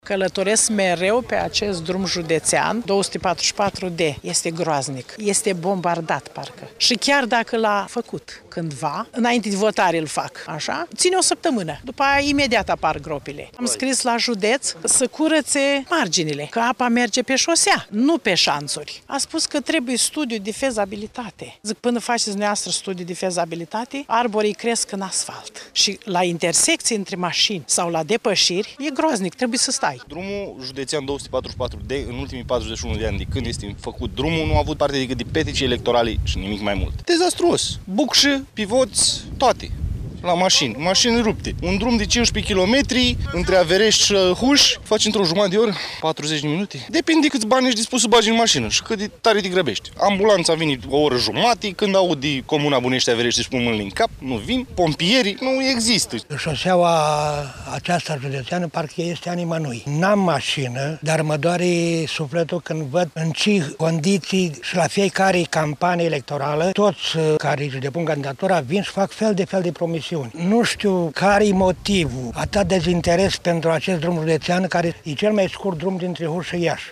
19-iun-rdj-17-vox-pop.mp3